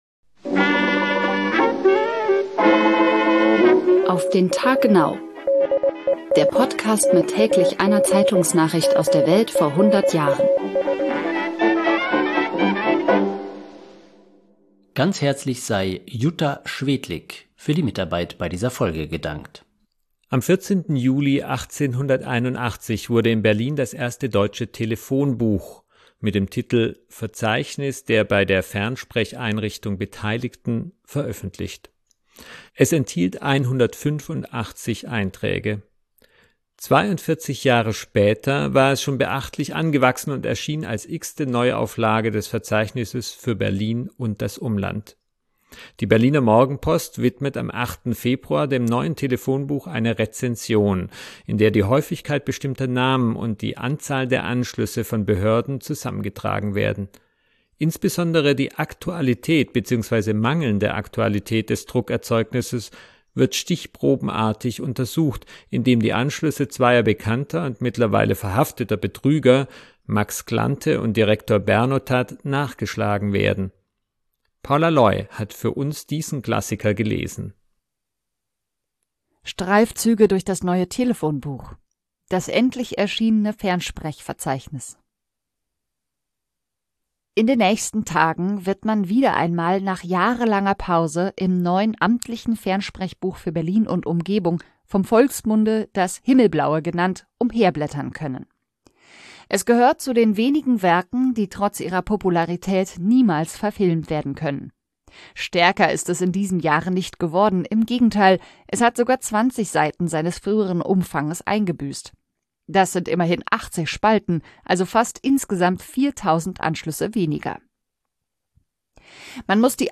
Der Podcast mit täglich einer Zeitungsnachricht aus der Welt vor hundert Jahren